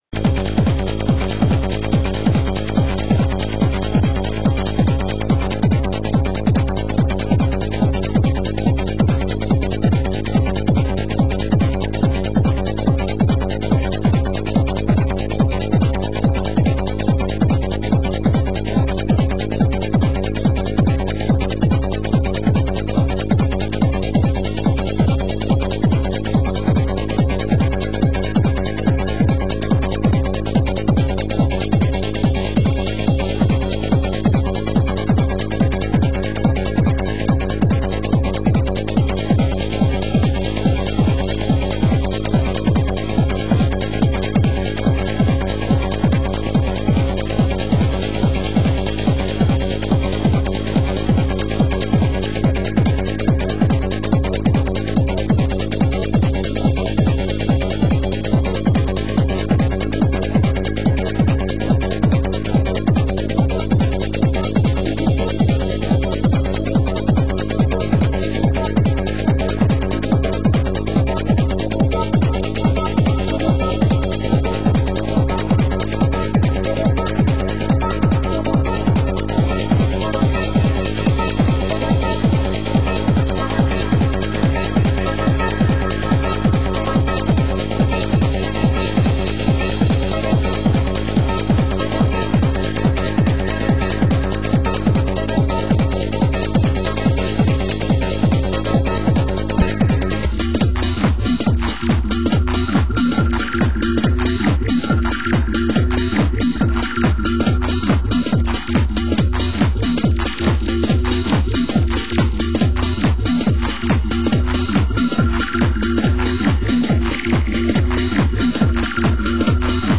Genre: Techno
Genre: UK Techno